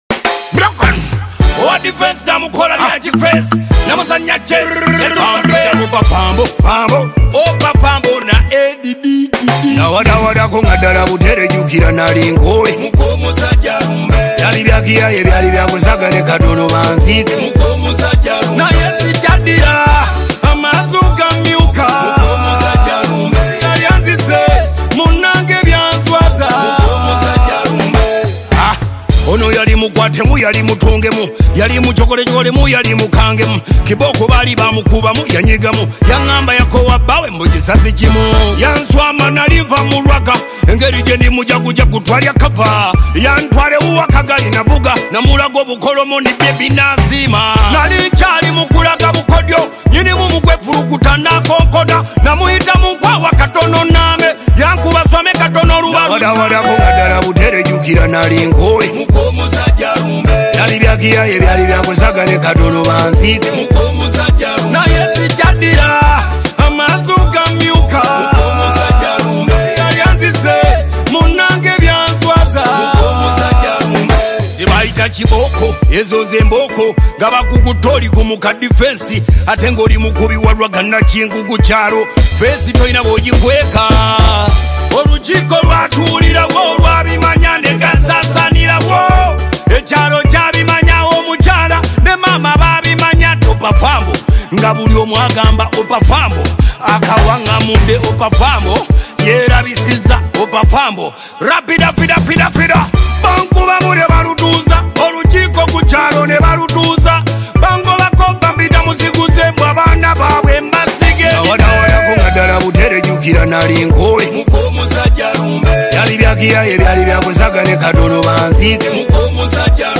DanceHall And Afro Beat